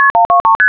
Generate Blue Box MF (multi-frequency) tones.